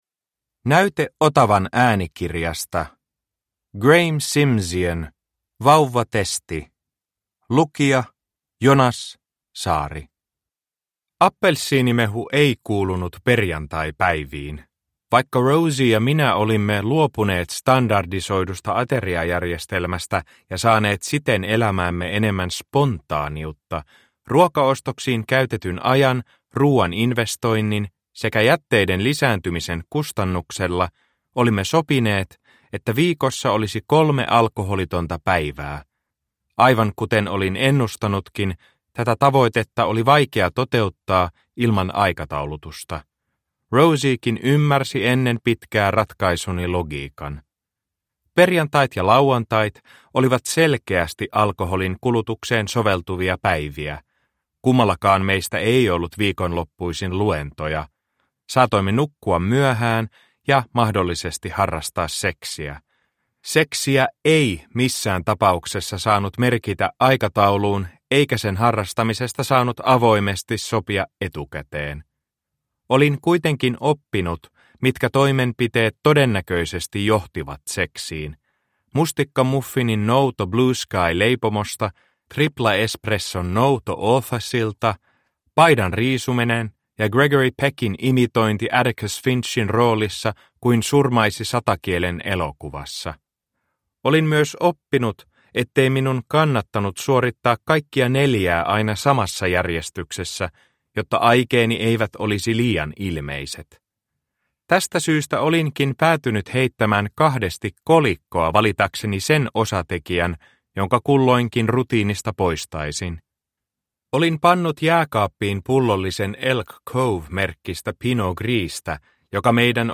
Vauvatesti – Ljudbok – Laddas ner